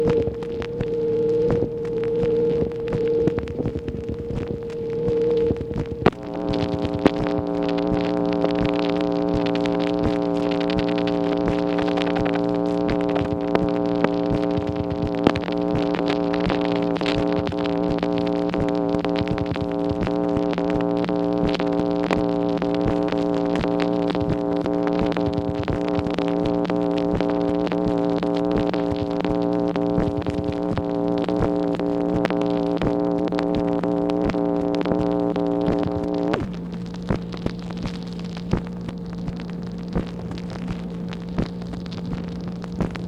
MACHINE NOISE, September 9, 1964
Secret White House Tapes